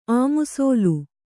♪ āmusōlu